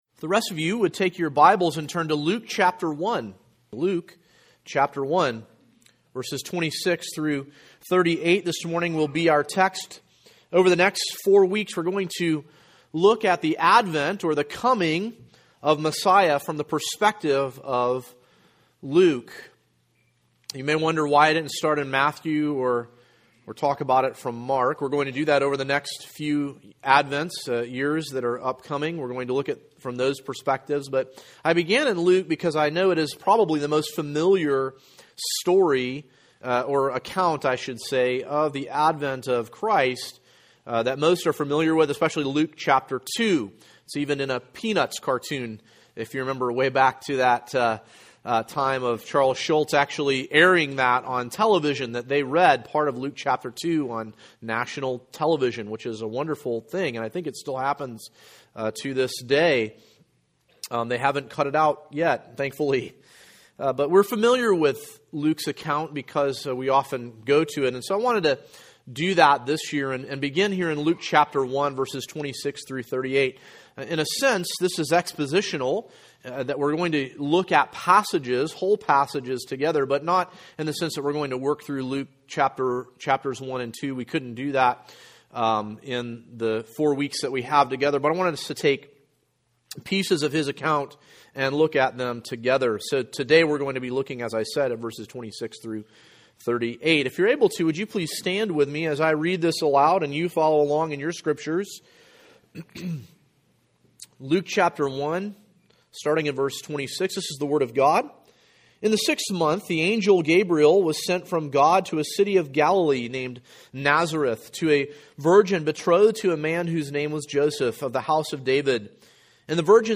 12.3.17 AM: The Announcement of Messiah | Fellowship Bible Church